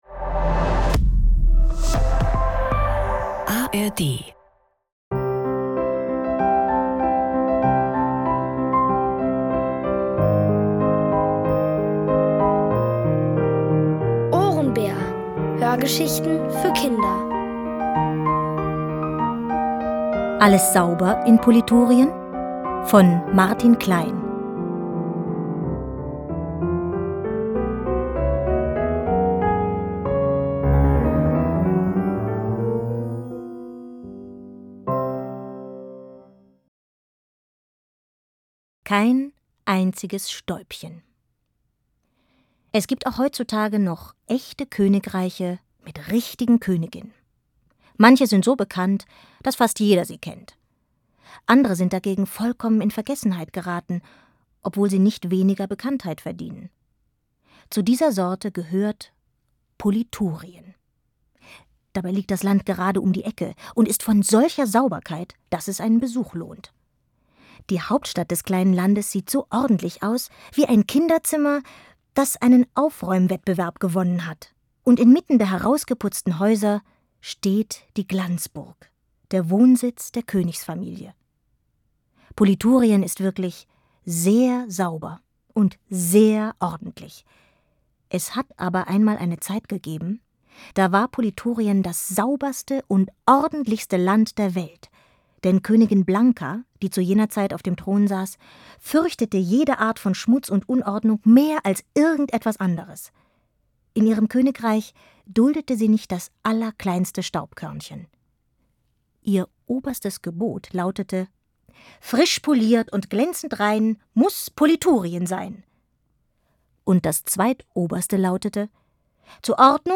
Alles sauber in Politurien? | Die komplette Hörgeschichte!